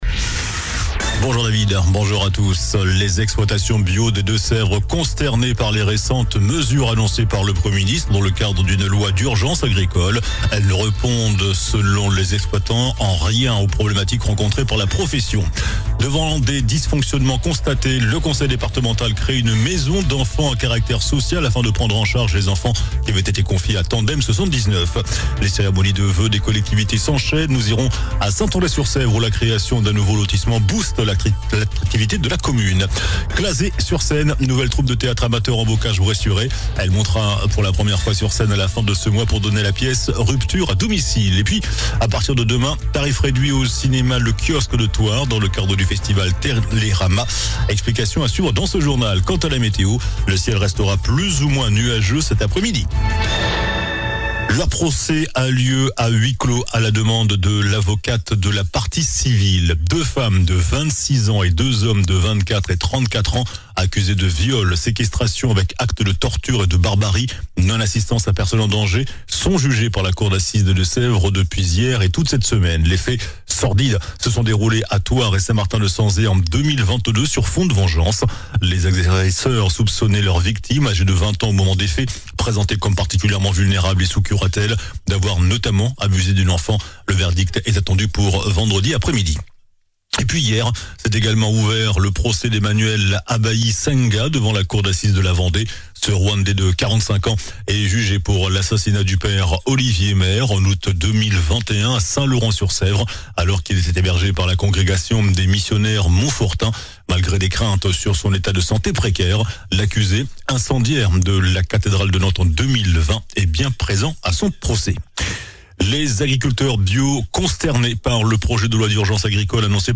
JOURNAL DU MARDI 20 JANVIER ( MIDI )